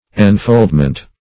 Enfoldment \En*fold"ment\, n. The act of infolding.